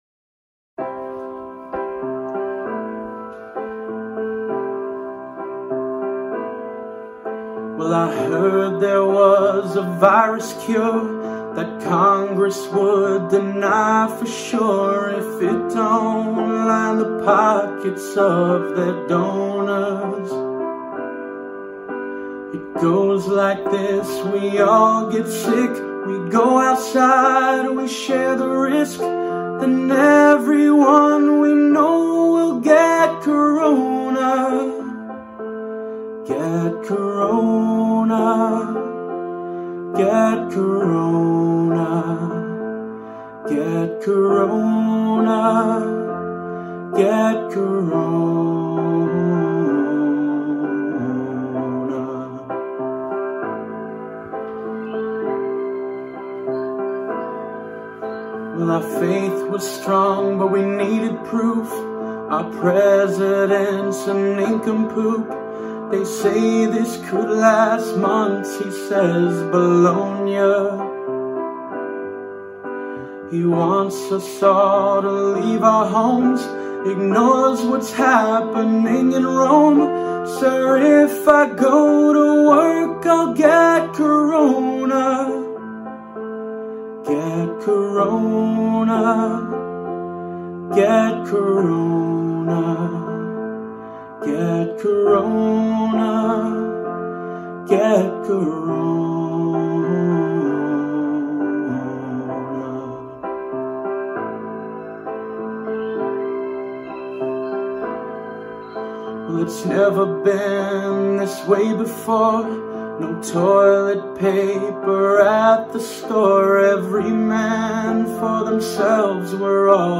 15) A Sixth Song Parody